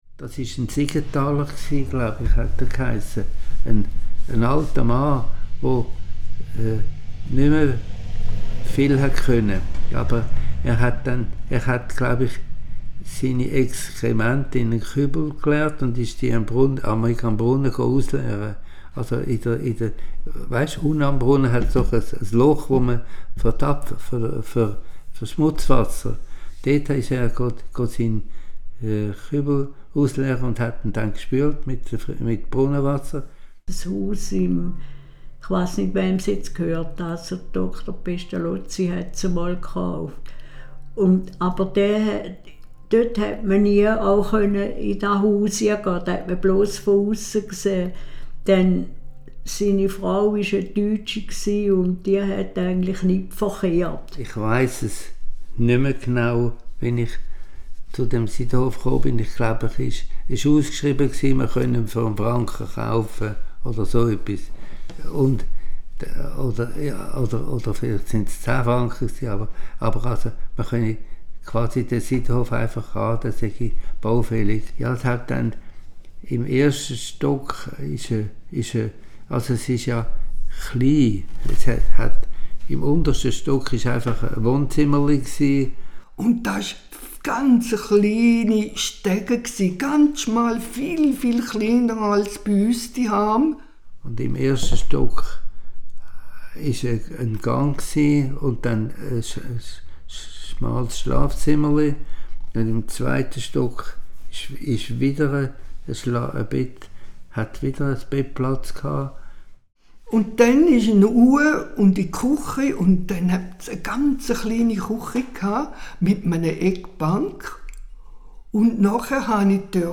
Dazu sollen Zeitzeugen, die mit den Gebäuden in Verbindung stehen, zu Wort kommen. In Tonaufnahmen mit Hilfe der Methode «Oral History» sollen diese Menschen zu ausgewählten Bauwerken erzählen und ihre persönlichen Erinnerungen und Erlebnisse teilen.